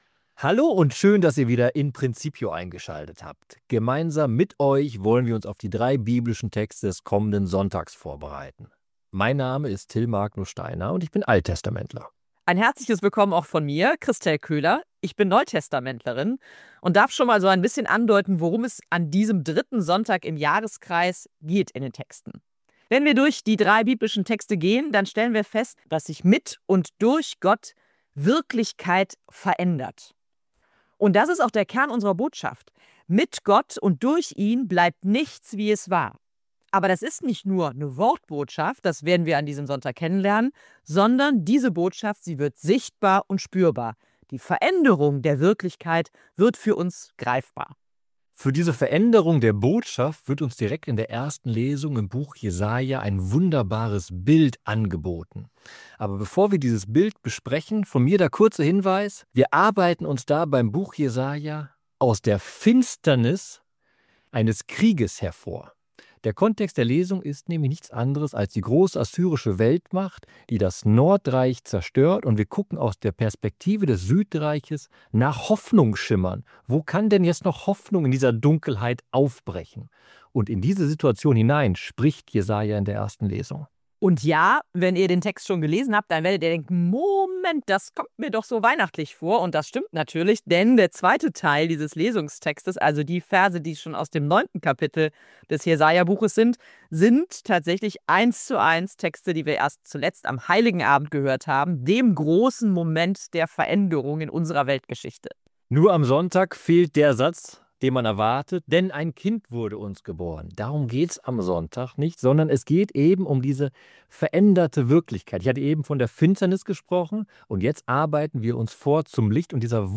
Diskussion